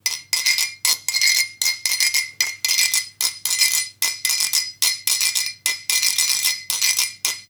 Rascar una botella de anís
percusión
ritmo
Instrumentos musicales